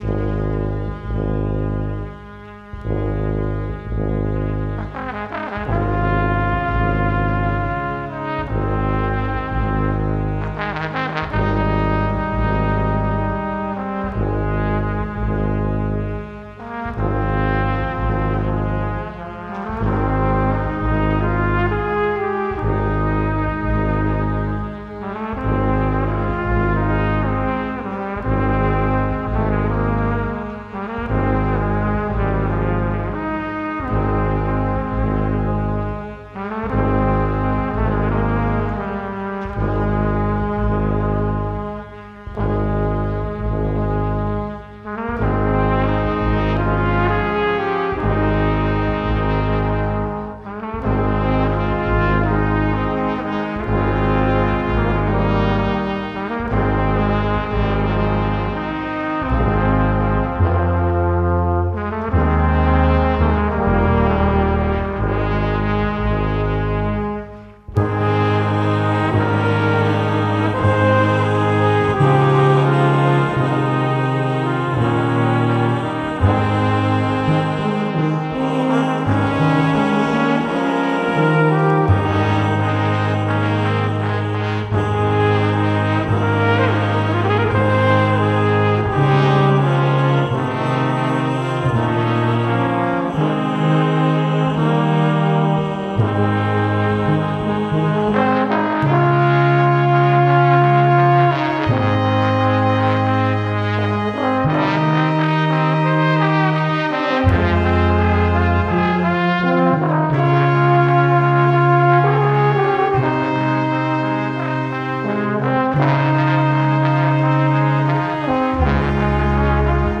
so, ich hab das mp3 genommen und ein wenig remastered.
unteren frequenzbereich die transienten etwas betont.
dann das ganze auf lufs -13 gebracht.